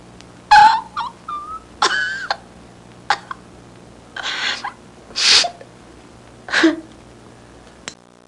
Crying (woman) Sound Effect
Download a high-quality crying (woman) sound effect.
crying-woman.mp3